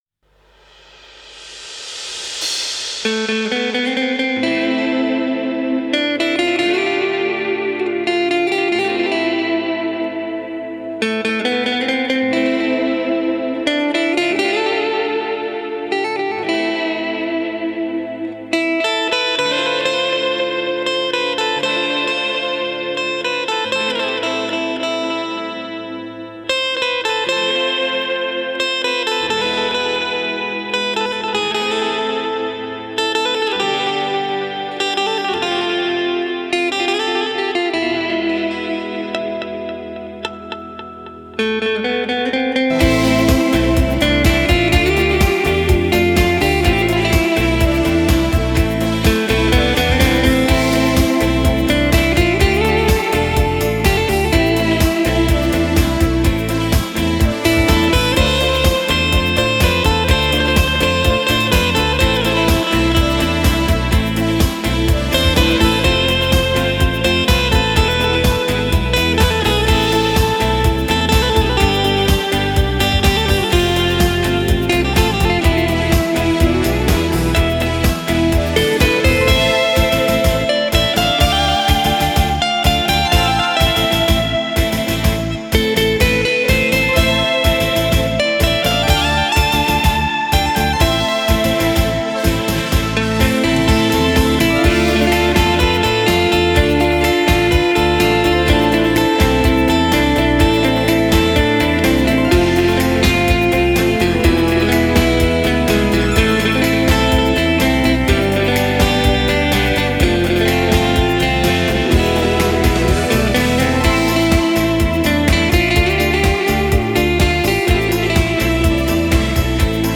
吉他
Жанр: Instrumental Guitar